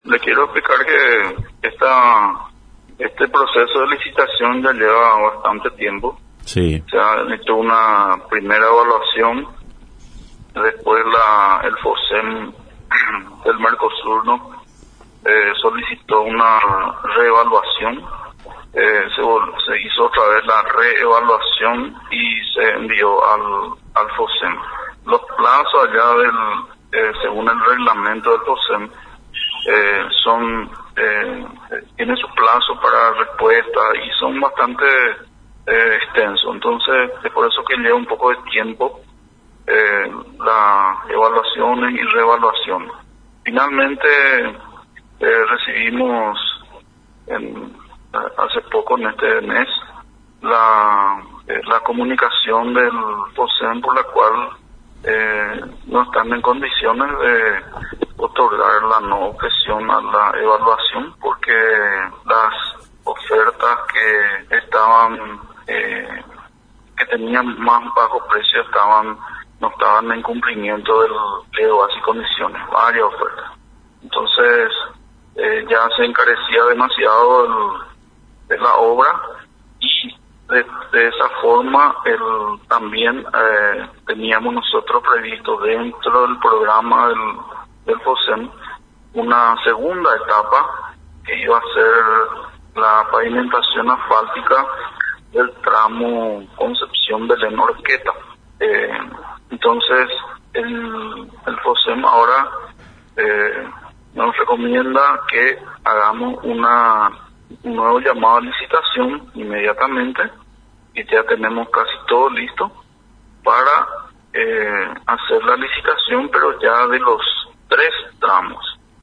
Desde el Ministerio de Obras Públicas y Comunicación, el viceministro Ing. Ignacio Gómez, informó a través de Radio Nacional San Pedro, que el proyecto de mejoras para la conectividad física de los departamentos de San Pedro y Concepción, será objeto de una nueva licitación donde se prevé incluir un lote más a los dos tramos